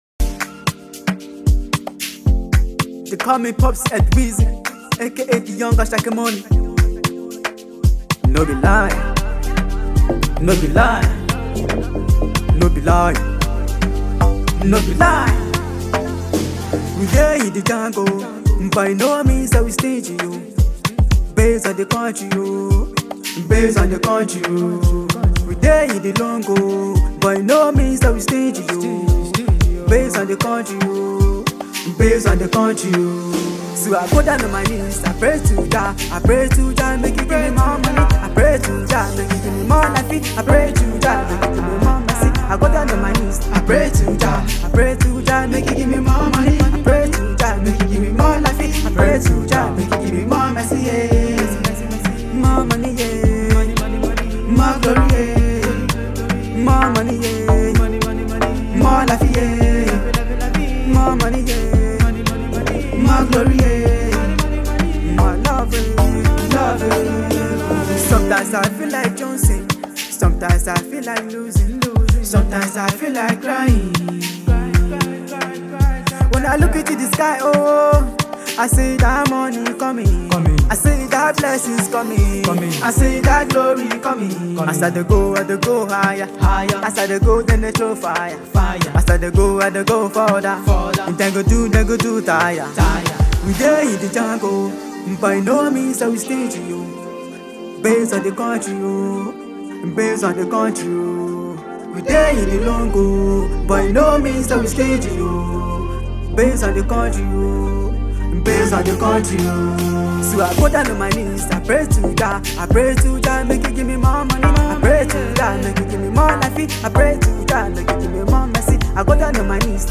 a Nigerian afro singer